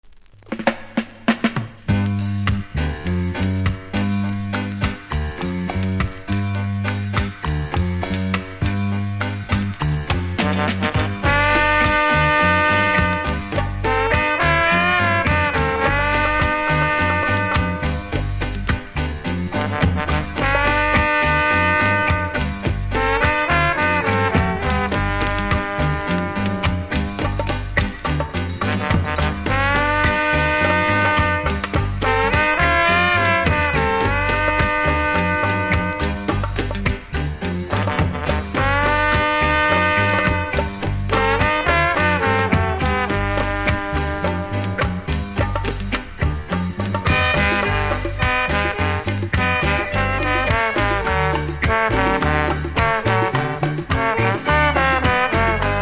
キズそこそこありますがノイズは少なく気にならない程度。